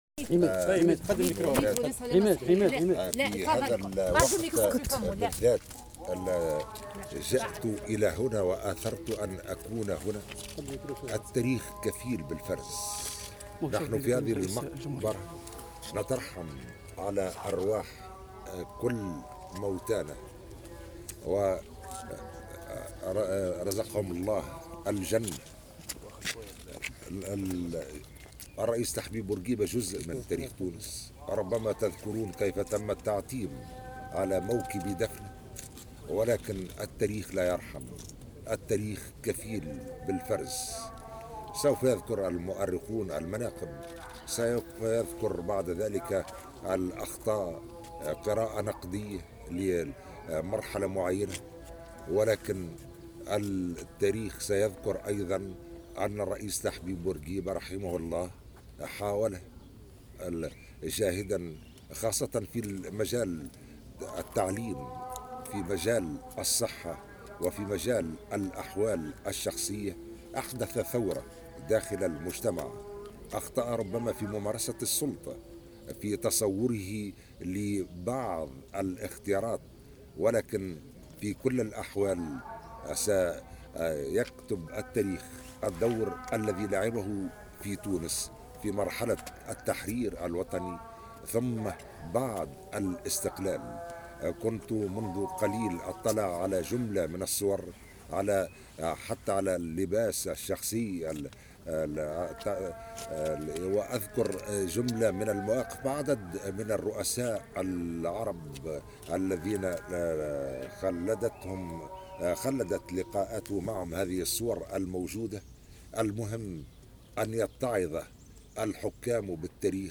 وفي تصريح أدلى به لـ "الجوهرة أف أم"على هامش هذه الزيارة، اعتبر قيس سعيّد الزعيم بورقيبة جزء من تاريخ تونس مذكرا بدوره الكبير في مجالات الصحة والتعليم والاحوال الشخصية ومعدّدا مناقبه.